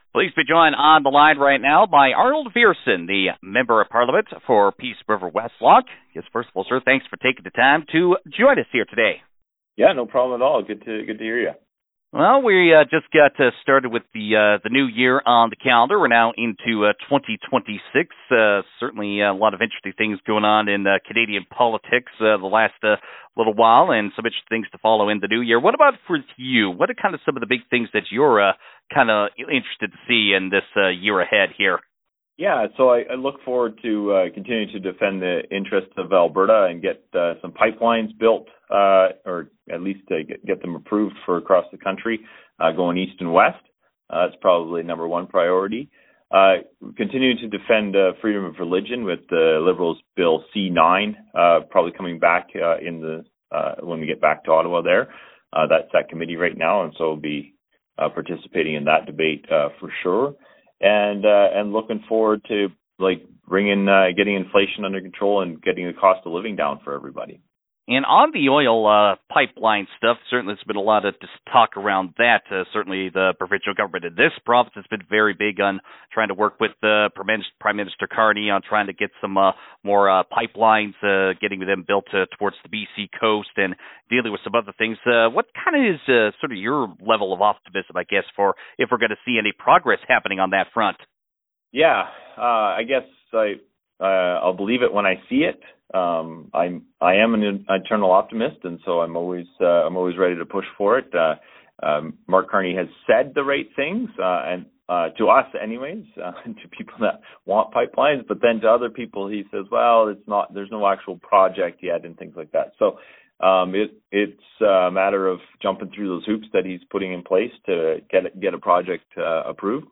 viersen-interview-jan-7.wav